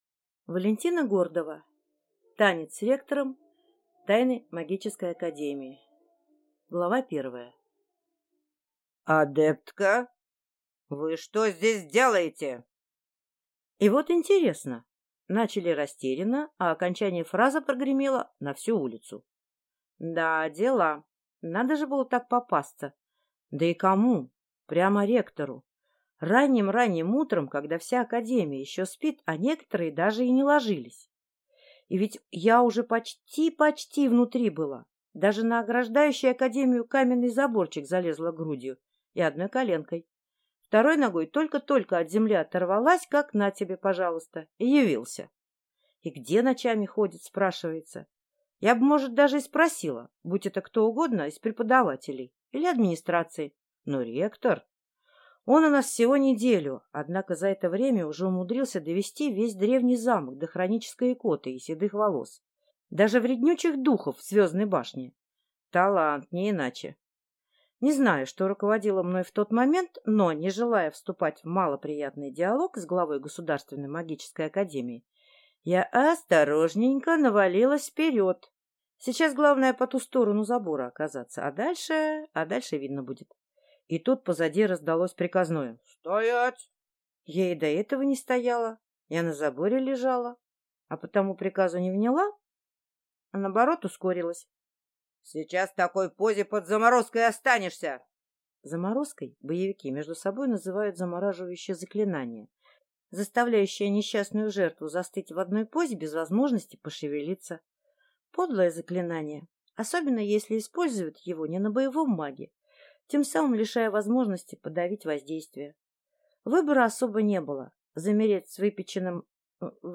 Аудиокнига Танец с ректором. Тайны магической академии | Библиотека аудиокниг